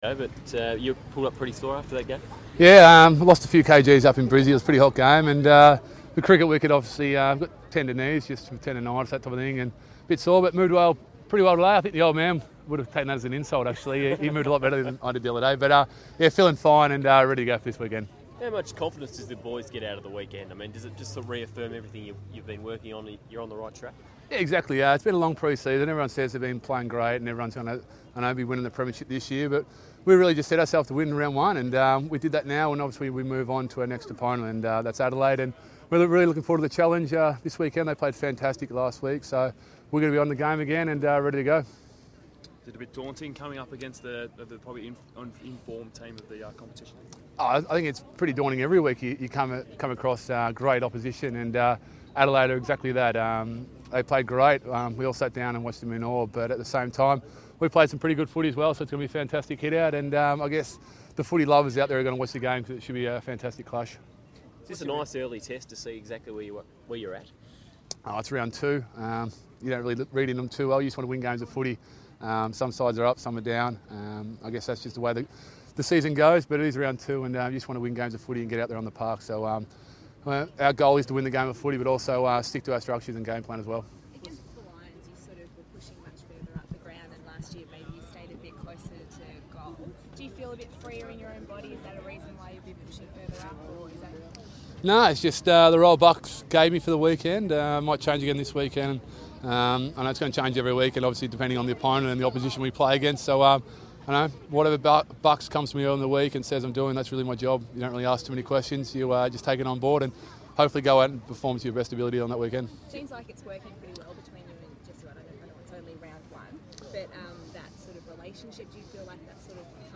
Press Conference: Travis Cloke
Hear from Travis Cloke as he faces the media ahead of Collingwood's clash with Adelaide at Etihad Stadium on Saturday evening.